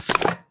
UI_Pickup_BoneTablet.ogg